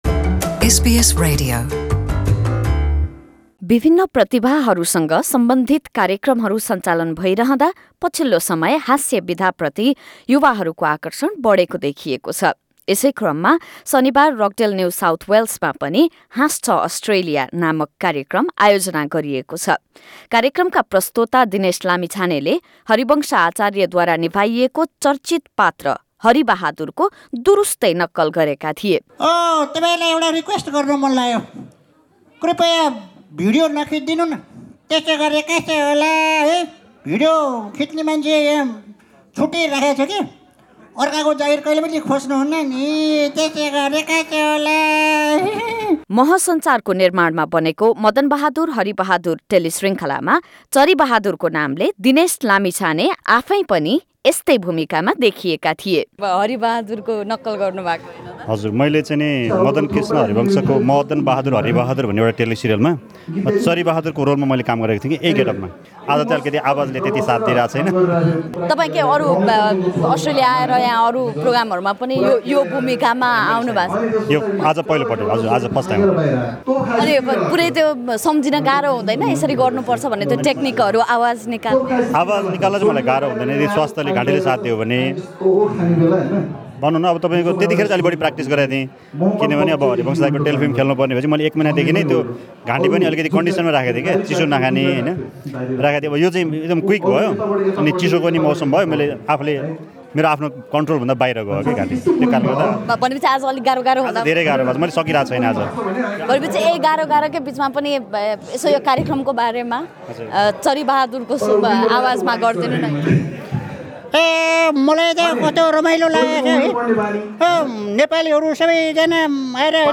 Source: sbs nepali कार्यक्रममा उपस्थित कलाकार तथा दर्शकहरूलाई स्ट्याण्ड अप कमेडी प्रति युवाहरूको आकर्षण र यससँग जोडिएका विभिन्न पाटोहरूबारे कुरा गरेका थियौँ।